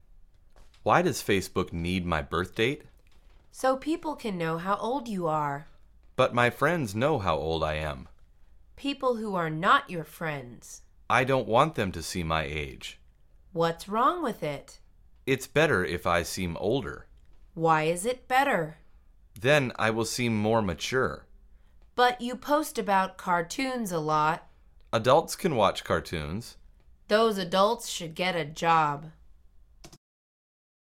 مجموعه مکالمات ساده و آسان انگلیسی – درس شماره چهارم از فصل شبکه اجتماعی: تولد